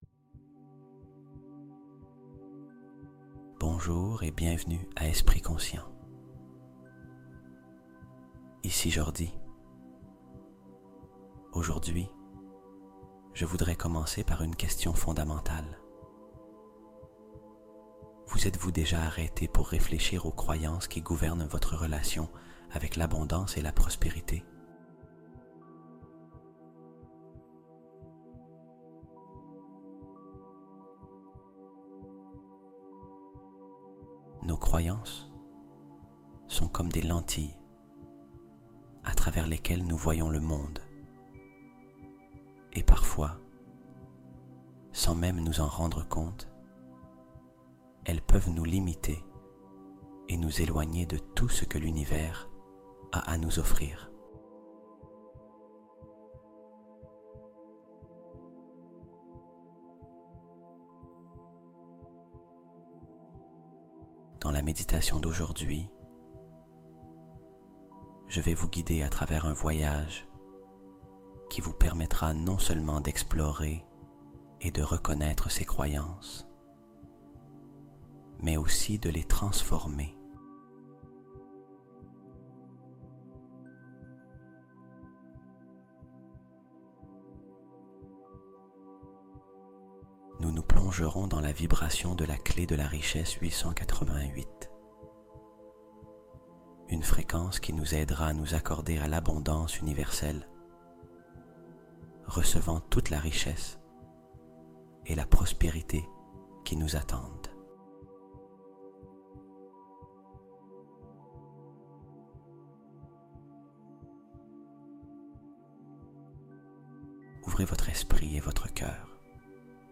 L'univers déverse SA richesse sur toi en ce moment | Fréquence 888 Hz d'abondance illimitée